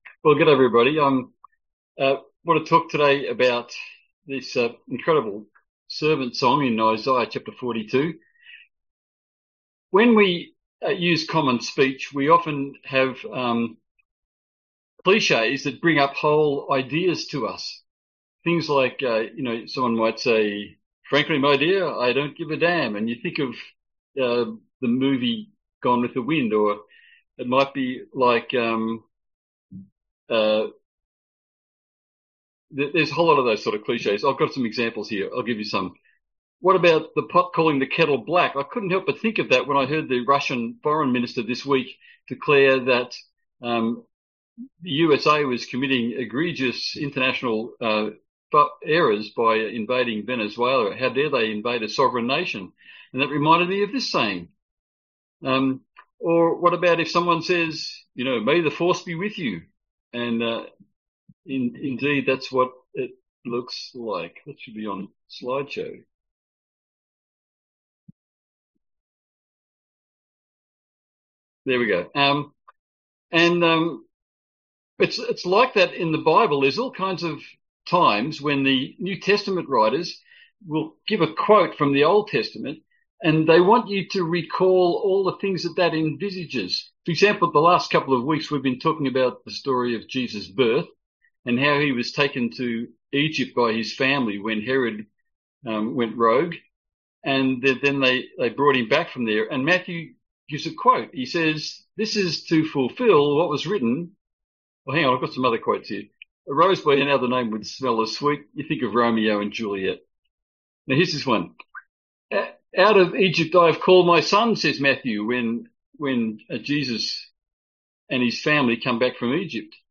A sermon on Isaiah 42:1-9 & Matthew 3:13-17